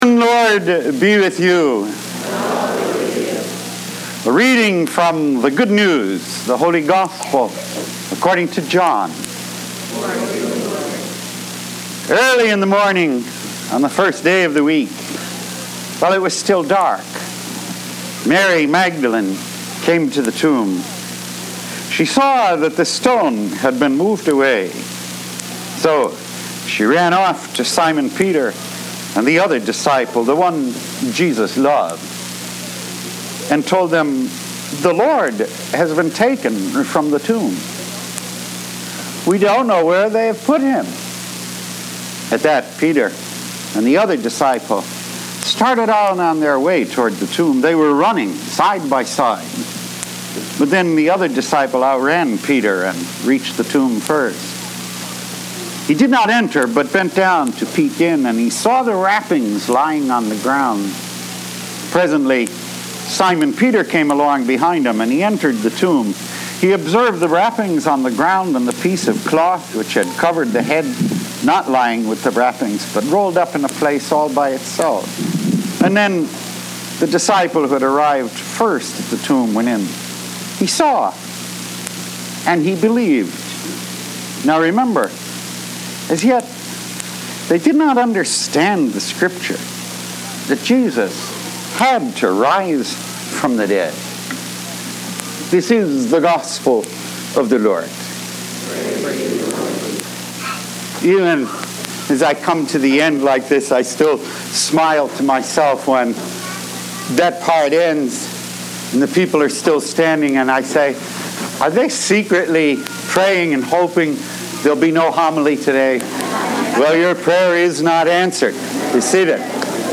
Light of Christ – Weekly Homilies